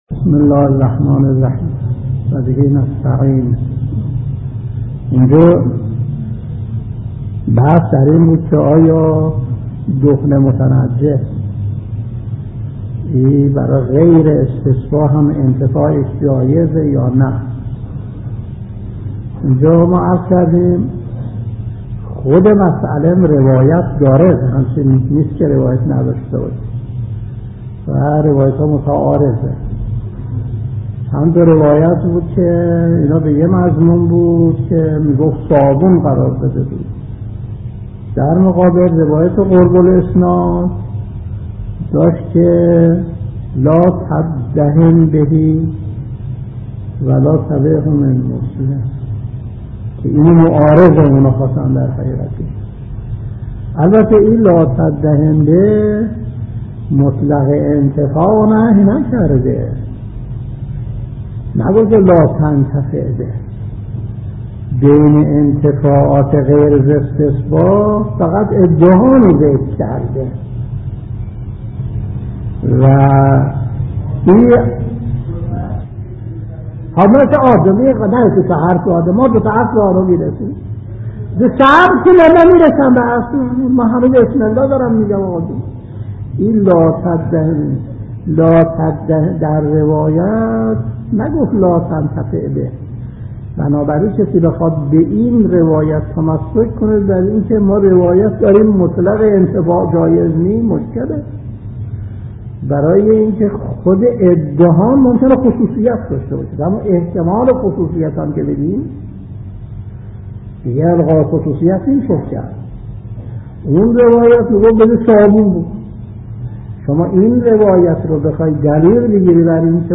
درس 250